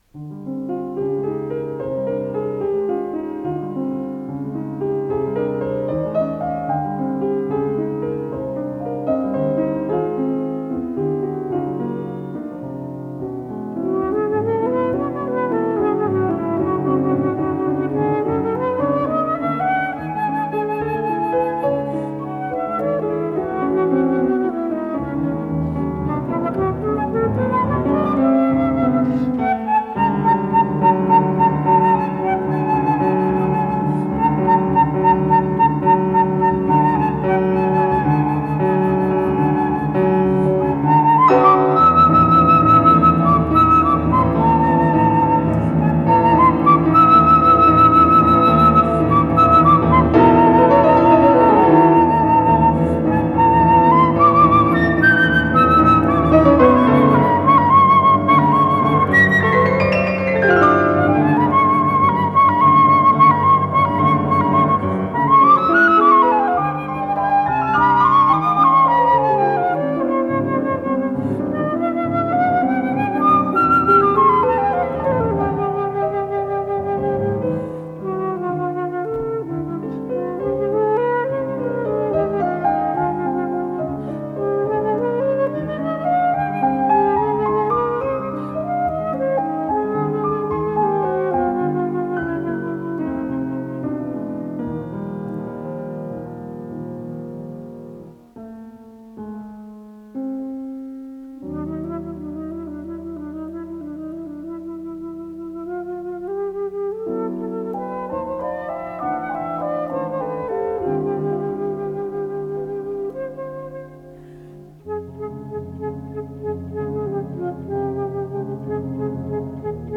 ДКС-32688 — Сонатина для флейты и фортепиано — Ретро-архив Аудио
с профессиональной магнитной ленты
флейта
фортепиано